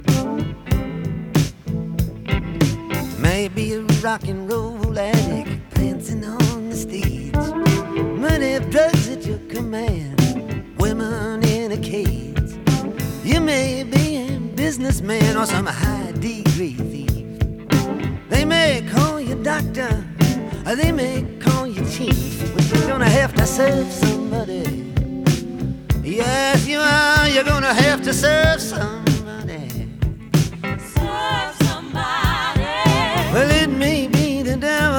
Жанр: Поп музыка / Рок / Фолк